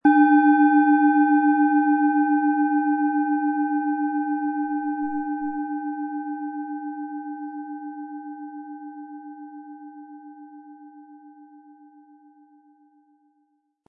Planetenton 1
Mars Planetenton-Klangschale, handgefertigt.
Spielen Sie die Schale mit dem kostenfrei beigelegten Klöppel sanft an und sie wird wohltuend erklingen.
Um den Originalton der Schale Mars anzuhören gehen Sie zu unserem Klangbeispiel.